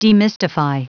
Prononciation du mot demystify en anglais (fichier audio)
Prononciation du mot : demystify